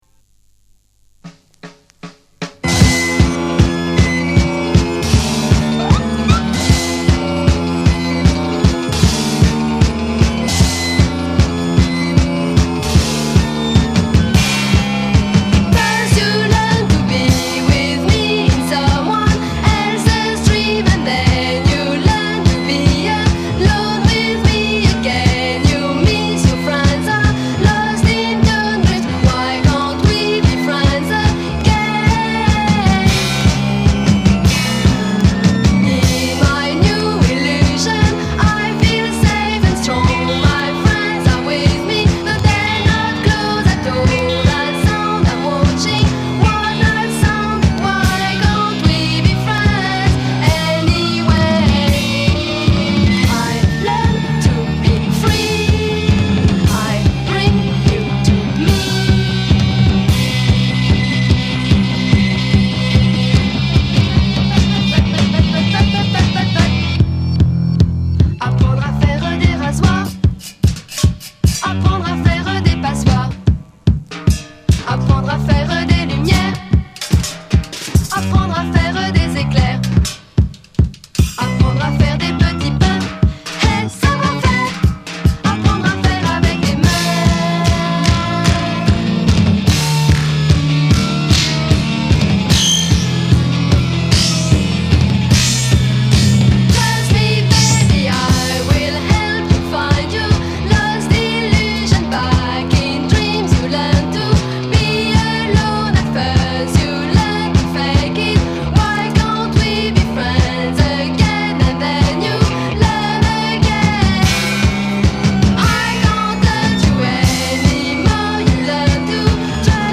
the center of it is an accordionist